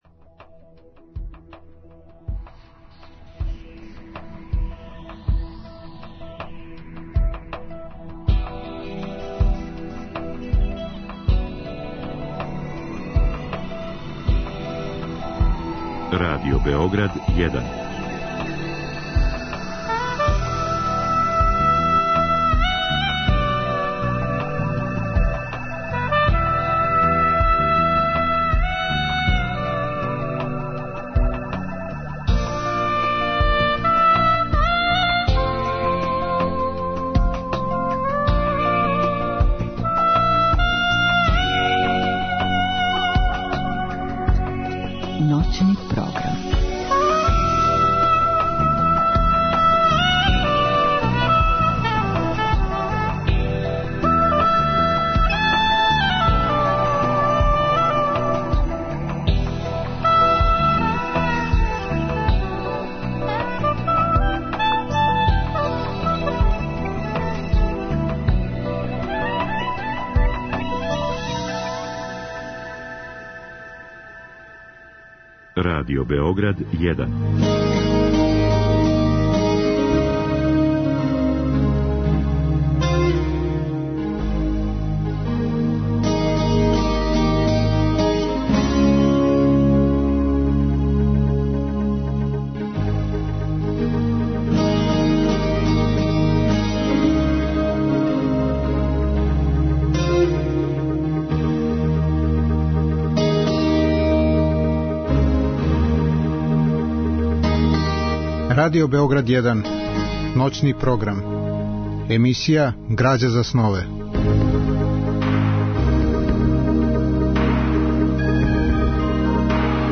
Разговор и добра музика требало би да кроз ову емисију и сами постану грађа за снове.
У другом делу емисије - од два до четири часа ујутро - слушаћемо одабране делове из радио-драма рађених по прози Едгара Алана Поа, писца чија дела садрже многе елементе сна.
Радио-драме су реализоване у продукцији Драмског програма Радио Београда.